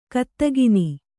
♪ kattagini